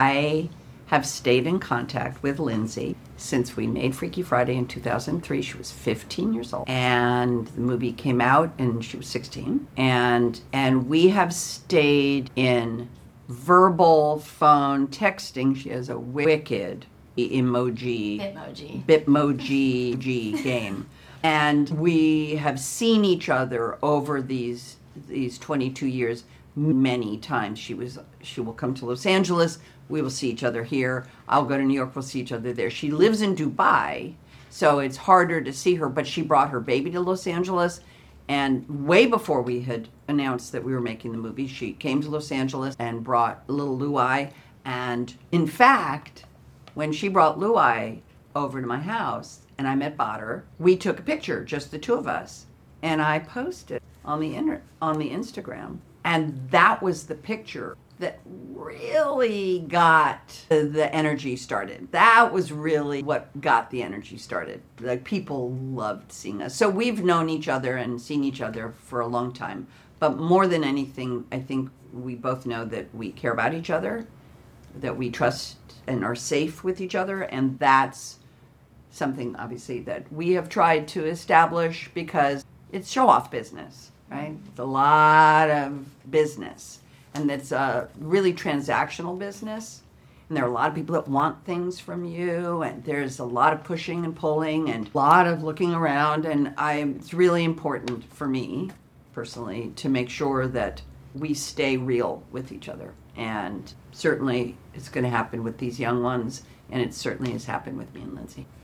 “I have stayed in contact with Lindsay since we made Freaky Friday in 2003,” said Jamie Lee Curtis during Freakier Friday press junket interviews.